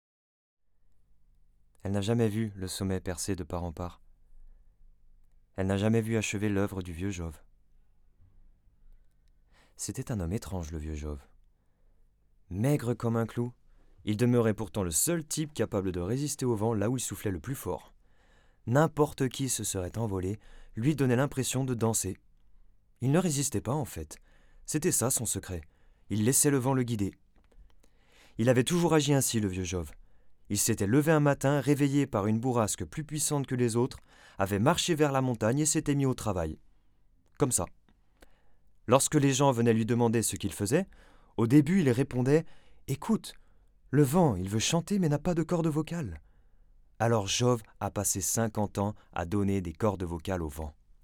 Voix off
Extrait livre audio
28 - 38 ans - Baryton